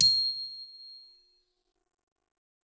glock_medium_C7.wav